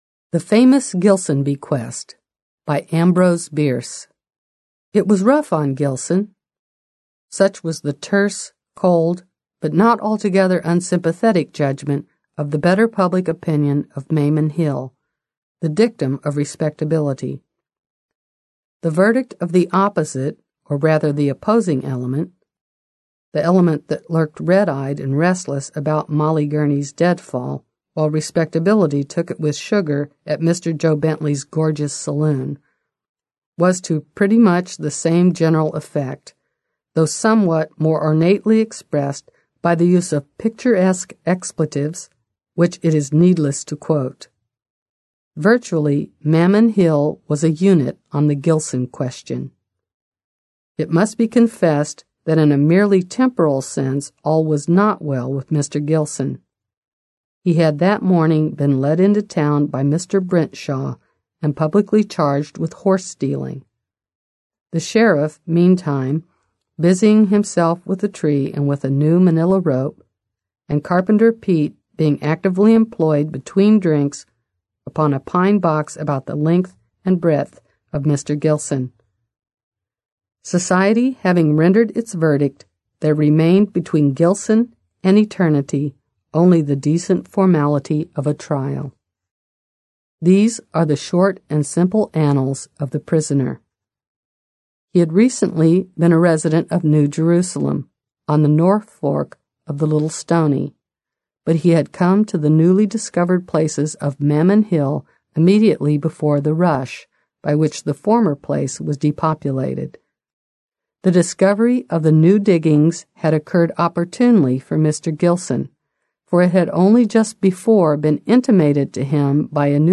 Аудиокнига Classic American Short Stories | Библиотека аудиокниг